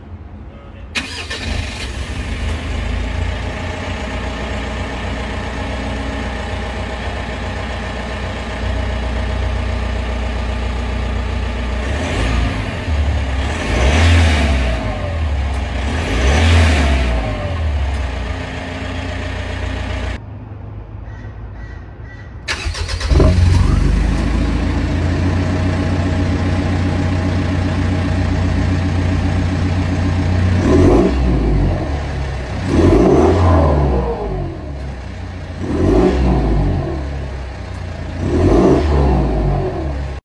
Check out the difference between stock and 3” Redback system with delete pipe